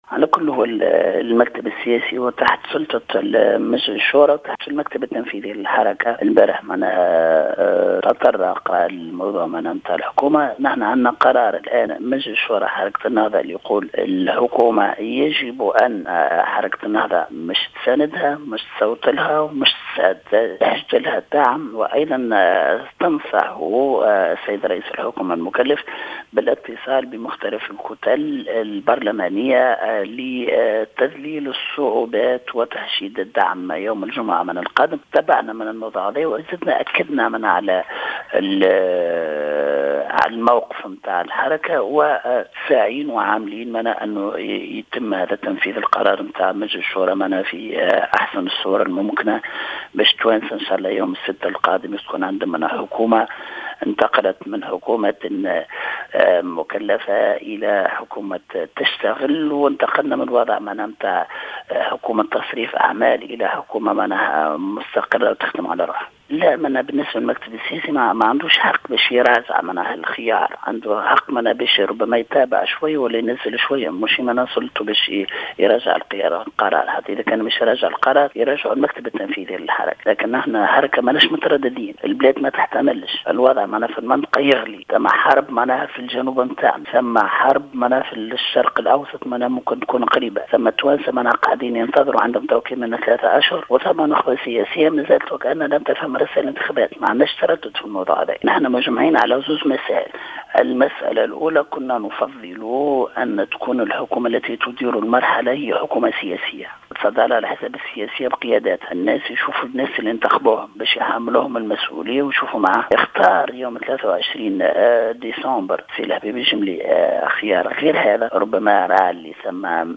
أكد القيادي بحركة النهضة عبد الحميد الجلاصي في تصريح للجوهرة "اف ام" اليوم الثلاثاء 7 جانفي 2020 أن المكتب السياسي للحركة إجتمع مساء أمس وتطرق لمسألة منح الثقة مضيفا أن القرار الصادر عن مجلس الشورى يقضي بمساندة حكومة الجملي و حشد الدعم لها.